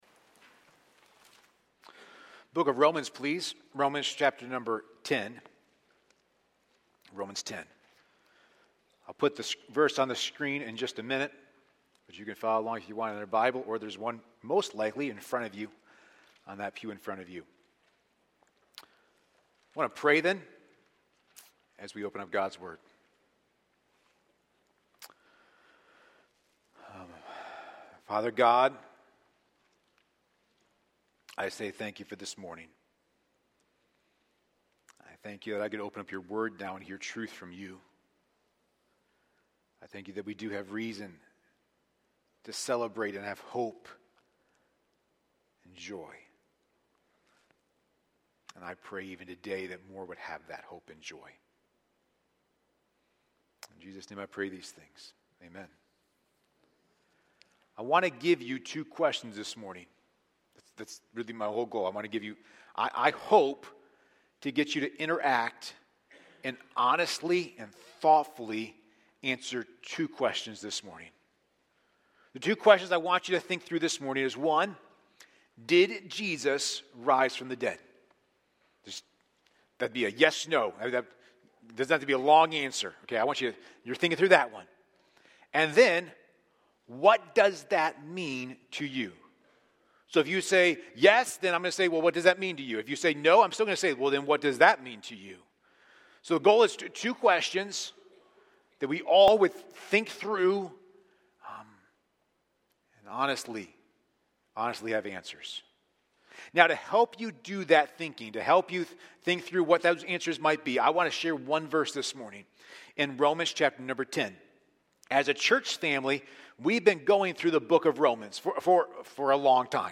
A sermon from the series "Romans."